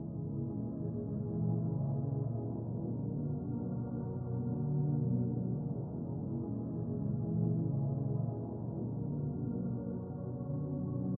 威胁垫圈
Tag: 86 bpm Trap Loops Pad Loops 1.88 MB wav Key : C